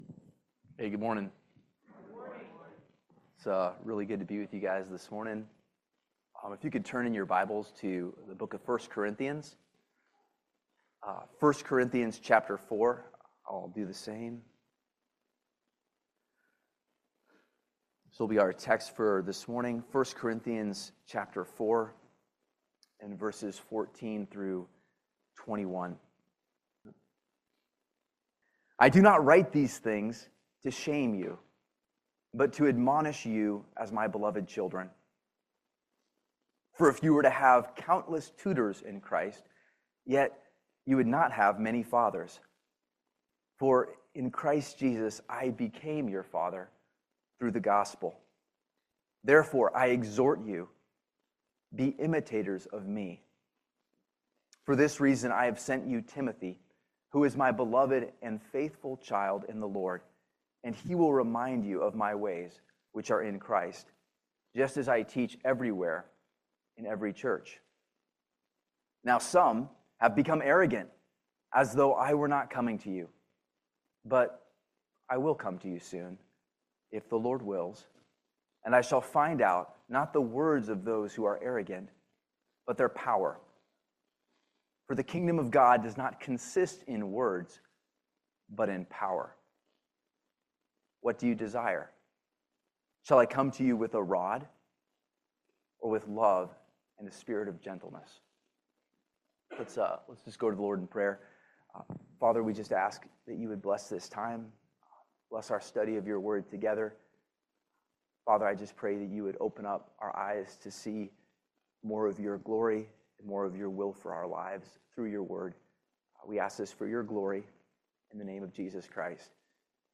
1 Corinthians 4 Service Type: Family Bible Hour Exercise spiritual fatherhood and receive it humbly.